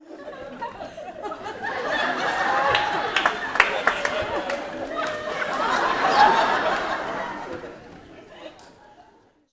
Audience Laughing-01.wav